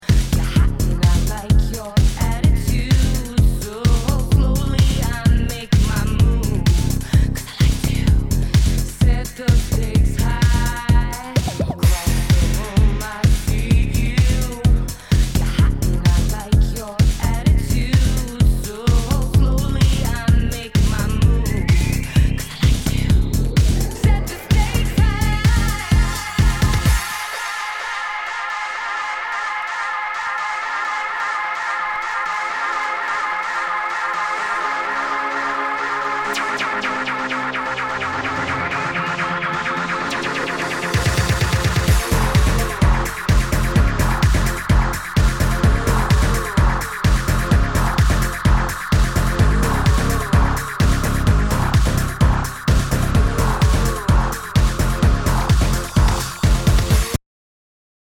[ Genre ] HOUSE/TECHNO/ELECTRO
ナイス！エレクトロ・ヴォーカル・ハウス!